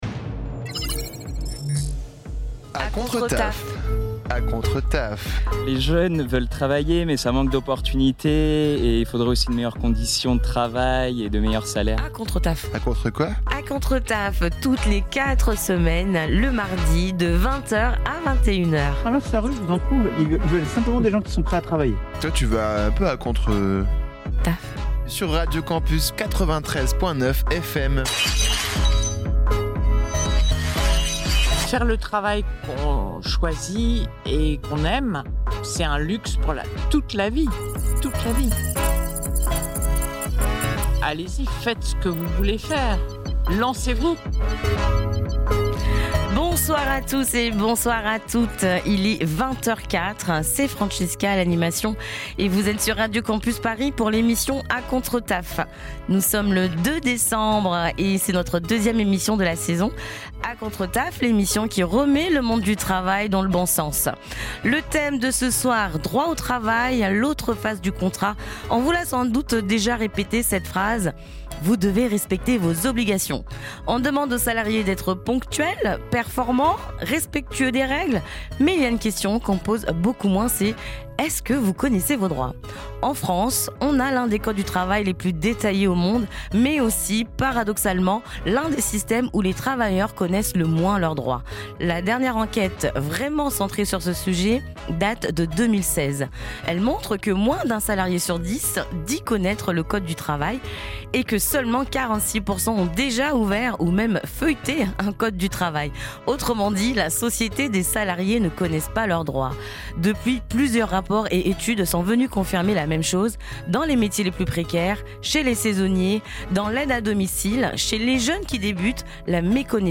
Type Magazine Société
La chronique humoristique